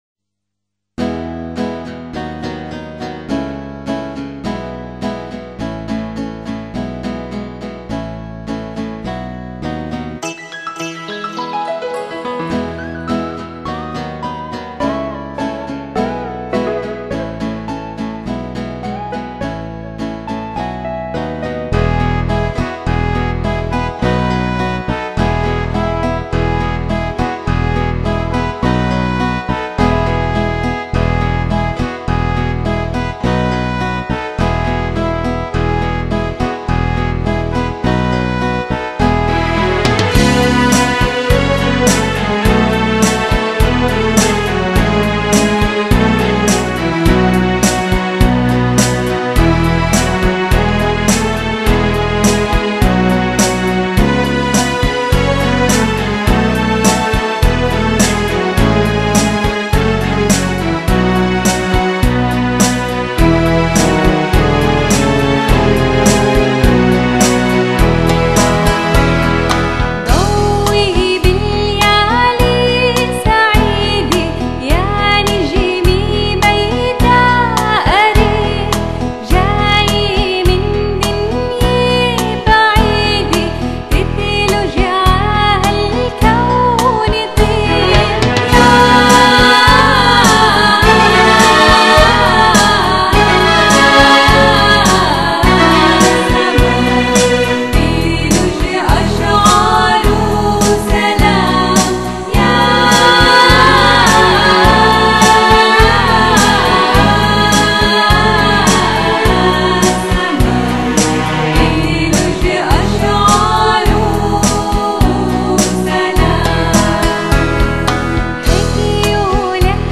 Genre: Pop,Folk,New Age